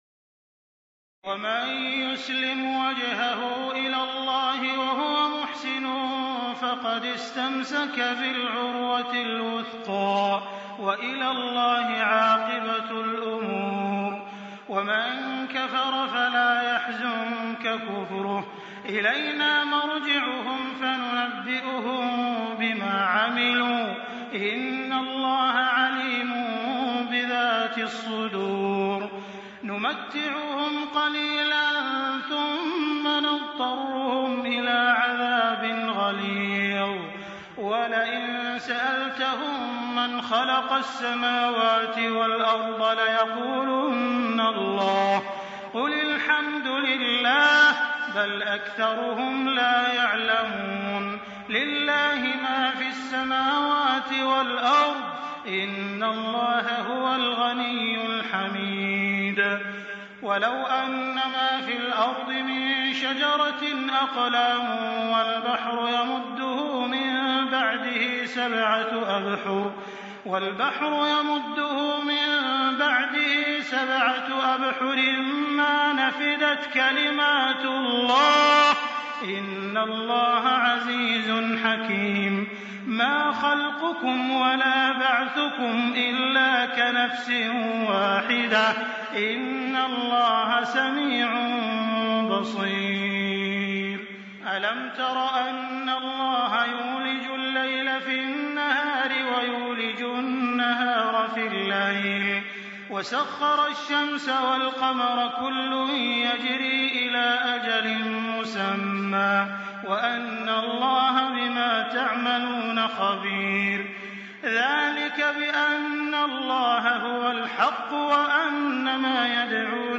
تراويح الليلة العشرون رمضان 1424هـ من سور لقمان (22-34) والسجدة و الأحزاب(1-34) Taraweeh 20 st night Ramadan 1424H from Surah Luqman and As-Sajda and Al-Ahzaab > تراويح الحرم المكي عام 1424 🕋 > التراويح - تلاوات الحرمين